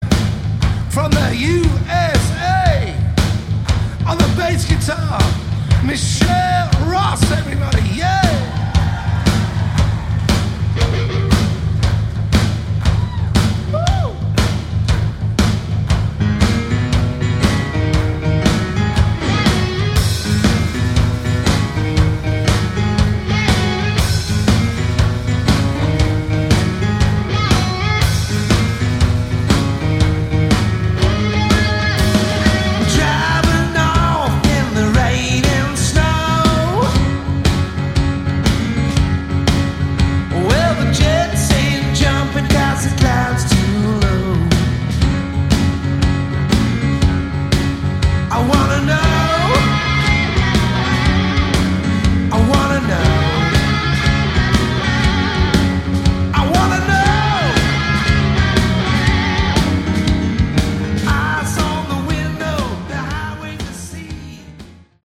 Category: Hard Rock
vocals, guitar, keyboards
drums
bass